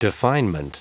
Prononciation du mot definement en anglais (fichier audio)